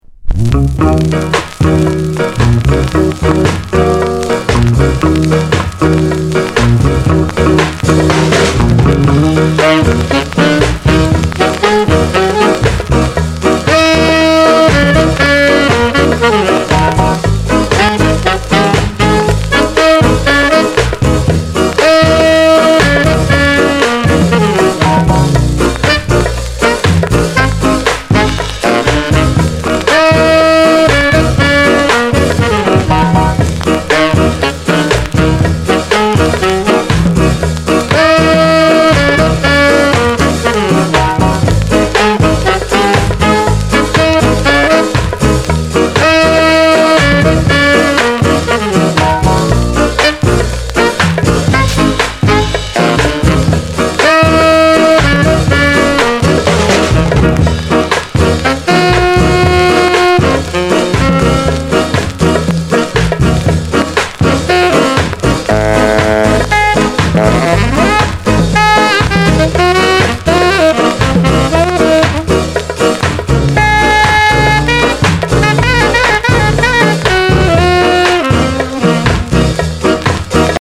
Genre:  Rocksteady